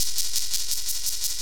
RI_ArpegiFex_170-03.wav